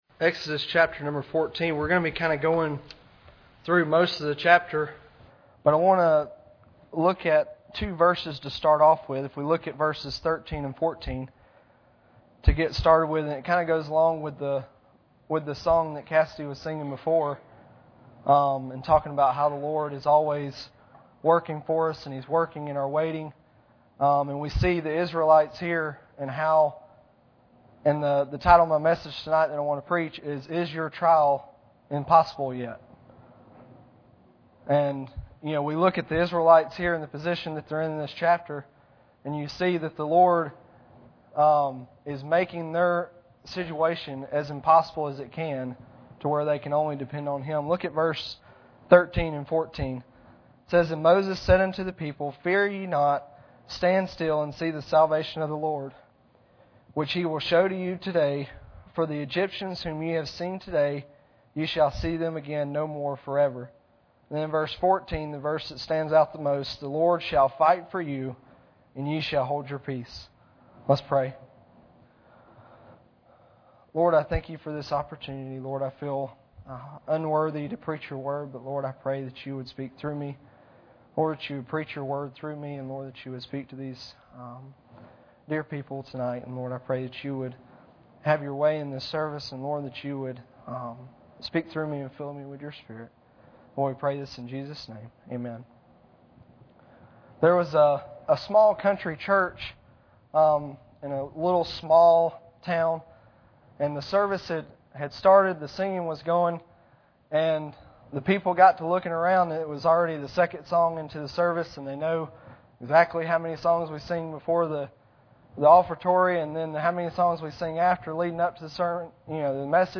During the sermon a thunderstorm was raging outside, and you may hear the church door slam 11:47 into the sermon as the wind blew it shut.
Service Type: Sunday Evening